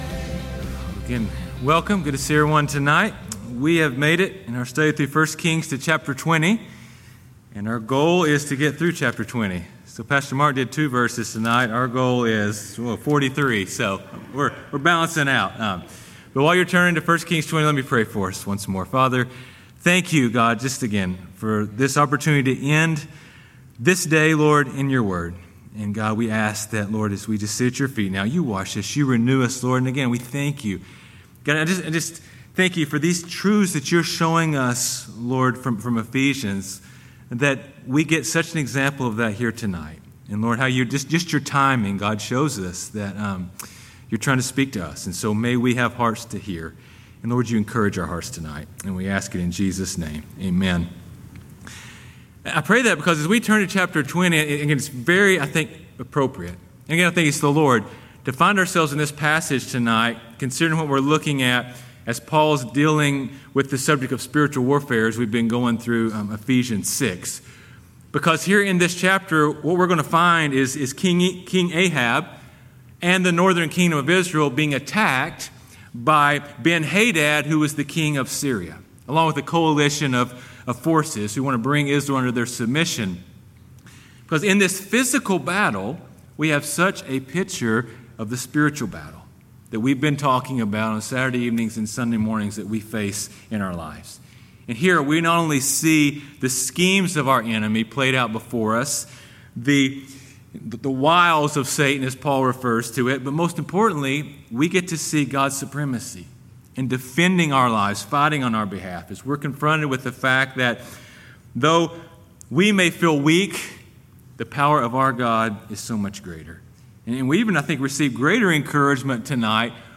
sermons 1 Kings 20:1-43 | The Enemy's Schemes and God's Supremacy